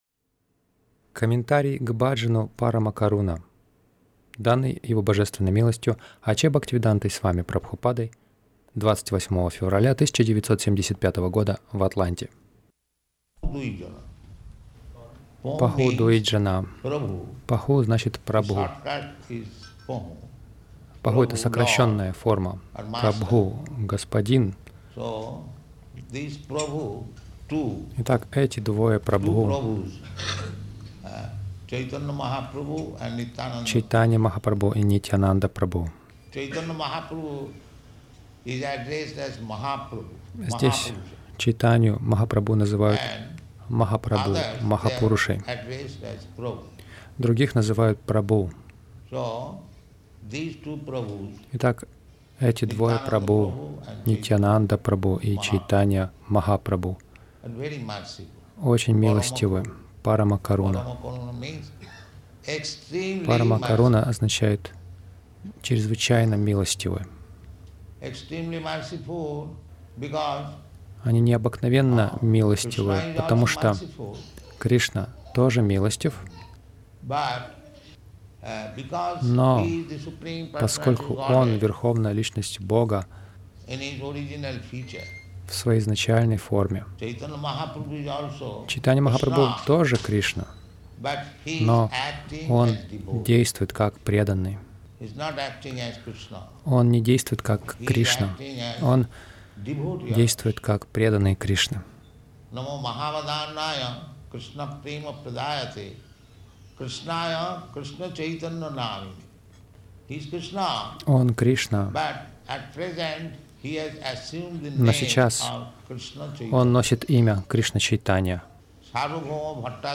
Комментарий к бхаджану Парама Каруна